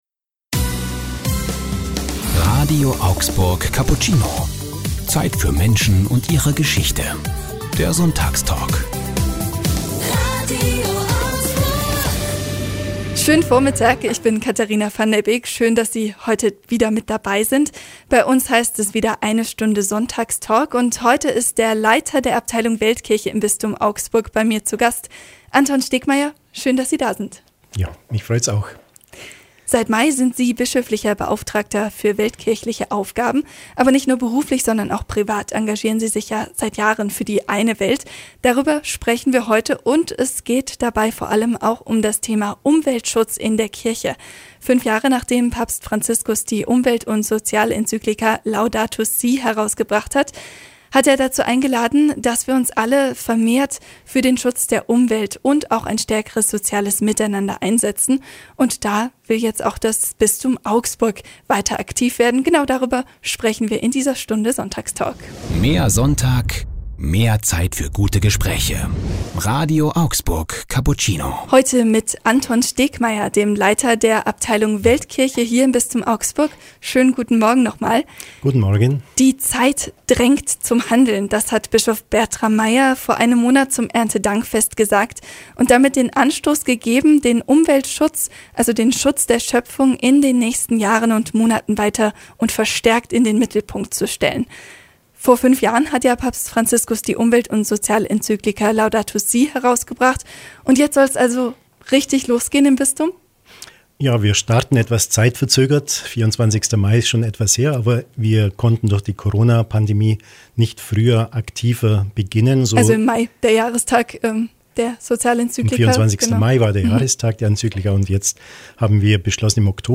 Beschreibung vor 5 Jahren Um die Bewahrung der Schöpfung ging es an diesem Sonntag beim Sonntagstalk "Cappuccino".